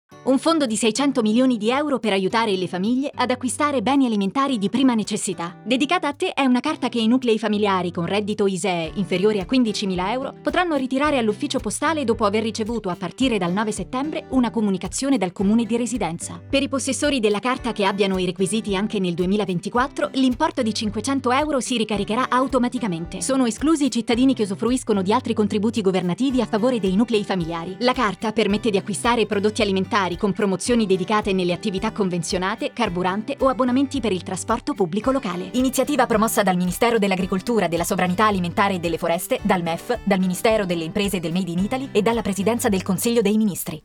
Lo spot radio
Il voiceover spiega che la card è rivolta alle famiglie con ISEE inferiore ai 15.000 euro e che non usufruiscono di altri contributi governativi.
dedicata-a-te_09-settembre-202_radiofonico.mp3